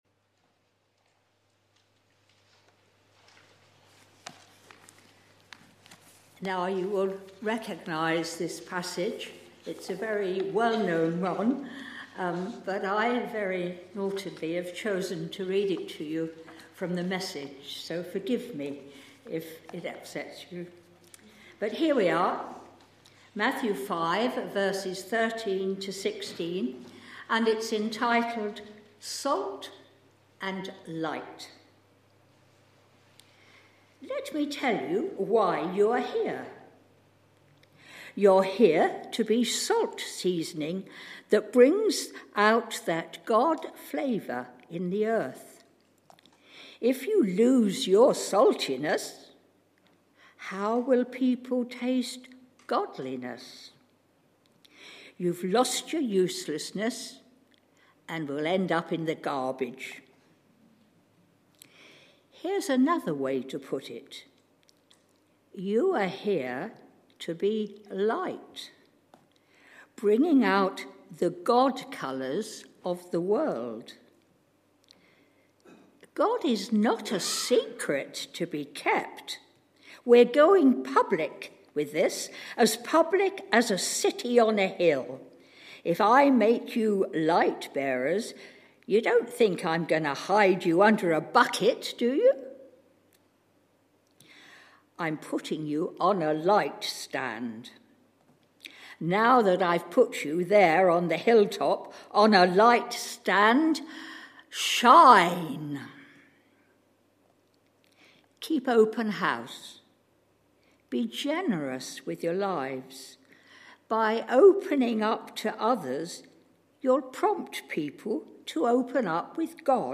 A talk from the series "Traditional Service."
Messages from our Traditional Services